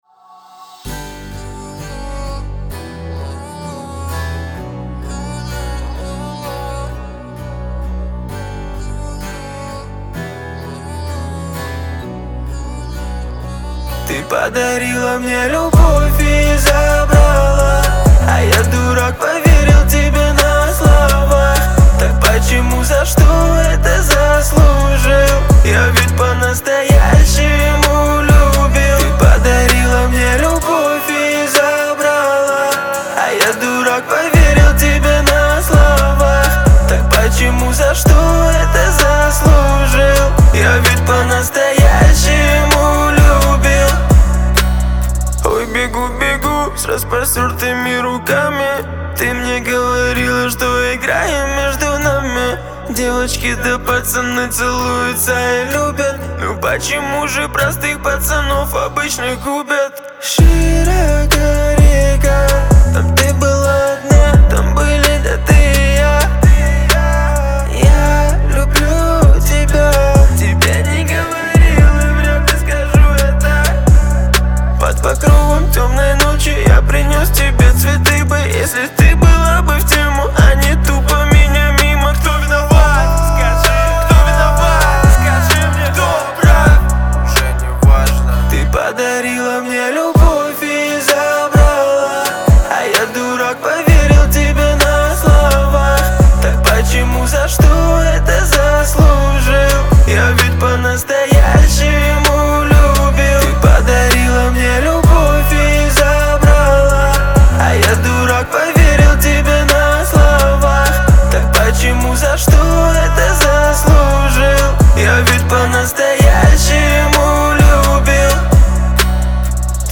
зажигательная песня в жанре поп с элементами R&B